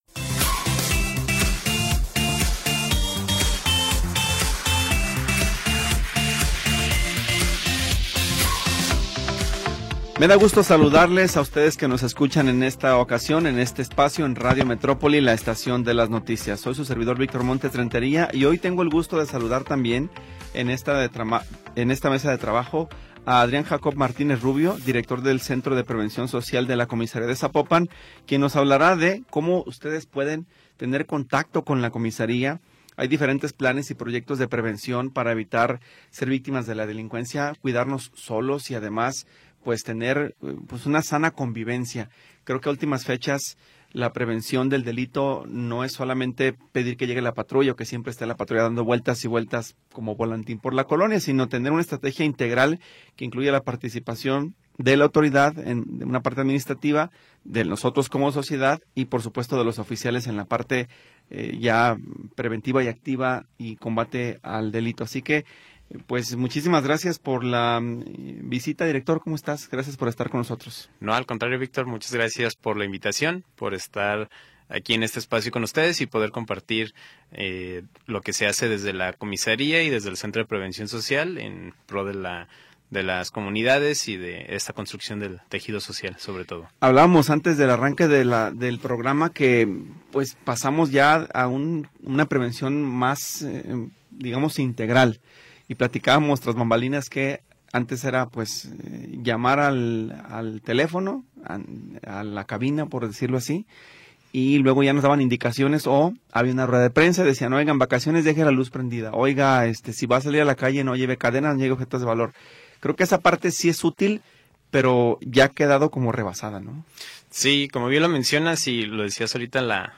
Programa transmitido el 12 de Diciembre de 2025.